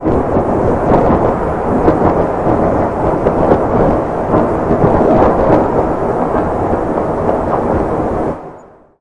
RG鸟飞
描述：鸟儿拍打着翅膀，正在飞行。人工。
Tag: 襟翼翼襟翼 机翼 翼瓣